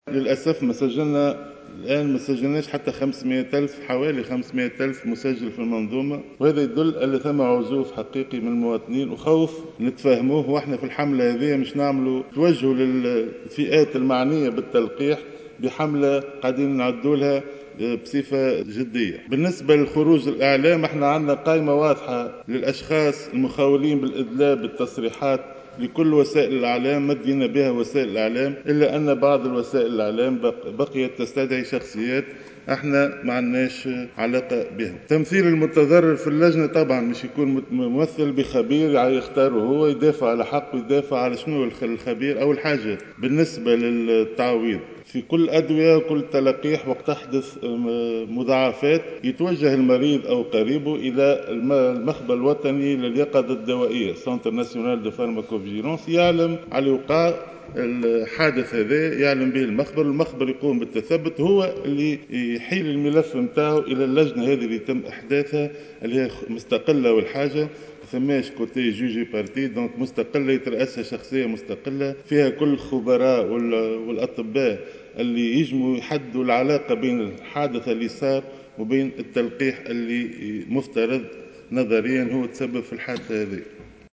وعبّر الوزير ردّا على تدخلات النواب خلال جلسة عامة خُصّصت للنظر في مشروع قانون يتعلق بأحكام استثنائية خاصة بالمسؤولية المدنية الناتجة عن استخدام اللقاحات والأدوية المضادة لفيروس كورونا المستجد (سارس كوفيد 2) وجبر الأضرار المُنجّرة عنه، عن تفهمه لمخاوف التونسيين من إجراء التلقيح، مشيرا إلى تنظيم حملة تحسيسية تستهدف الفئات المعنية بالتلقيح.